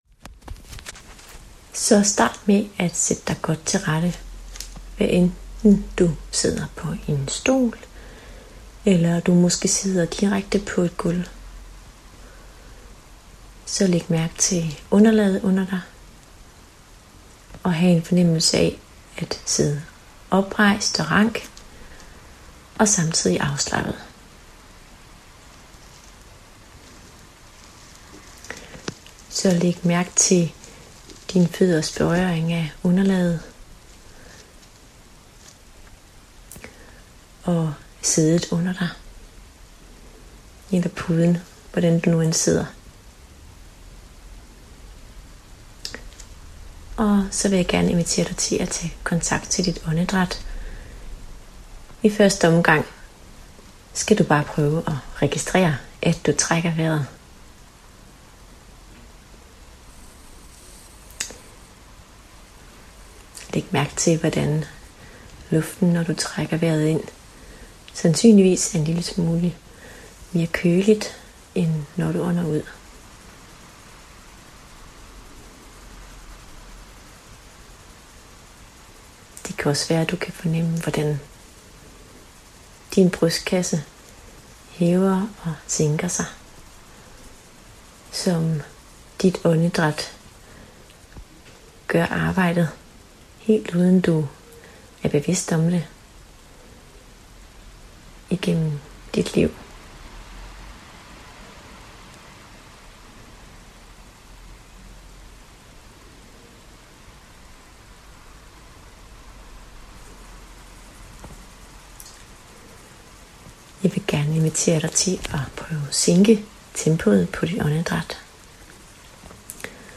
Guidede Meditationer
Beroligende-andedraet-bolger-2.mp3